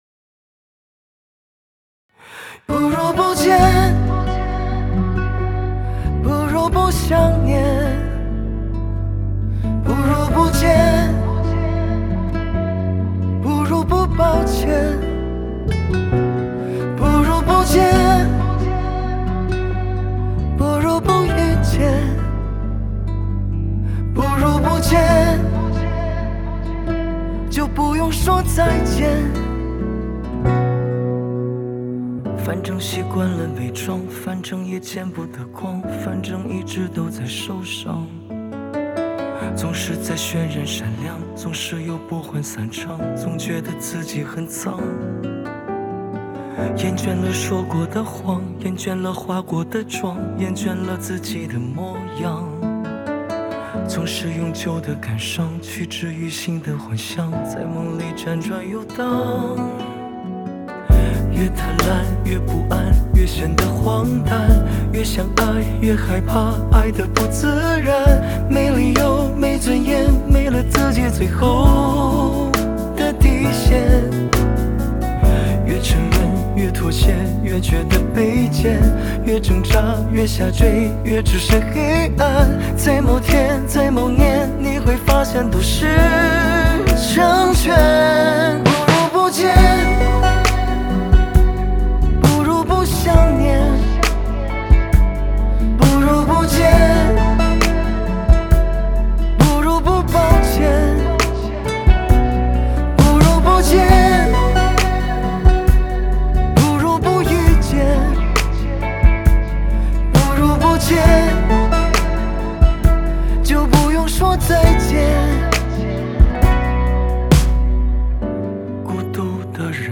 Ps：在线试听为压缩音质节选，体验无损音质请下载完整版
录音室：八度空间 (南京)
混音室：251 Music Studio